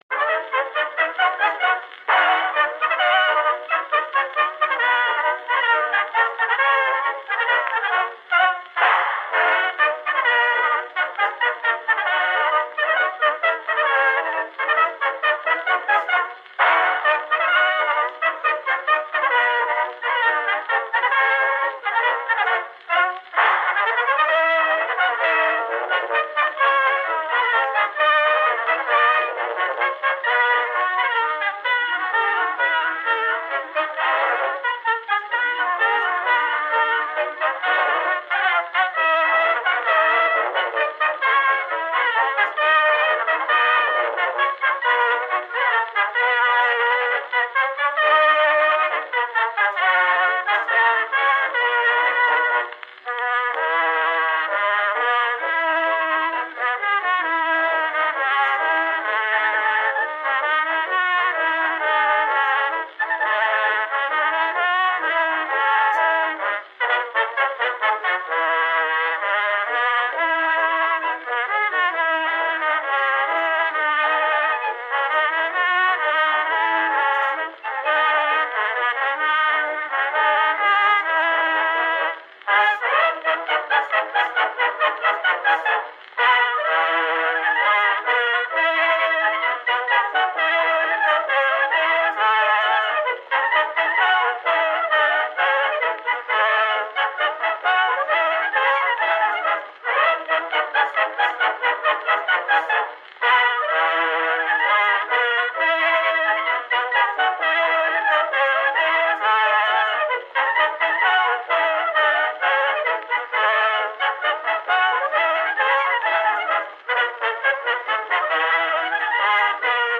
Граммофон - Gramophone
Отличного качества, без посторонних шумов.